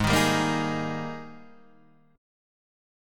G# Major 7th Suspended 2nd